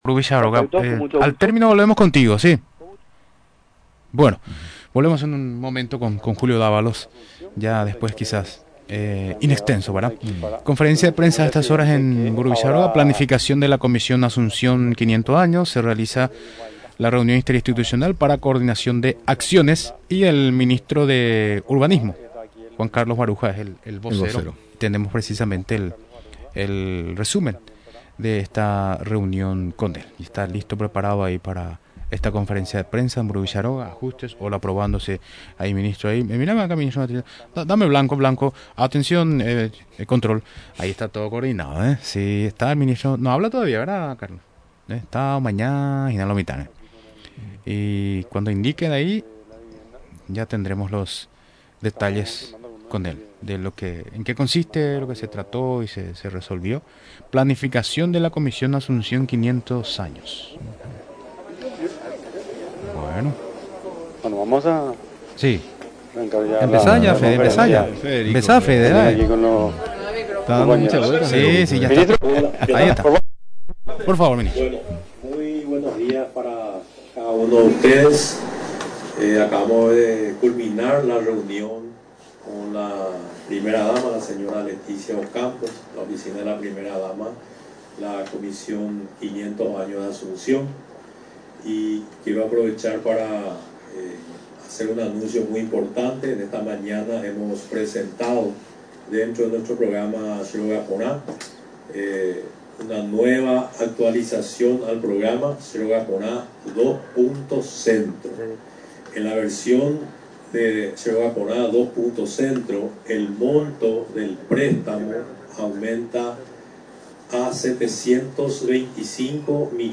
Esta mañana hemos presentado dentro de nuestro programa Che Róga Porã, una nueva actualización al programa Che Róga Porã 2.Centro, en el cual el monto del préstamo aumenta a 725 millones de guaraníes», explicó durante la rueda de prensa en Mburuvichá Róga, el ministro de Urbanismo, Vivienda y Hábitat, Juan Carlos Baruja.